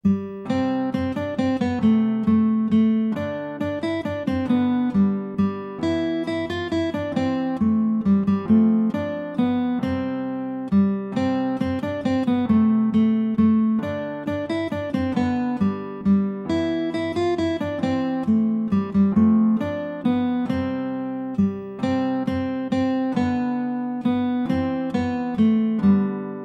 Categoría Navidad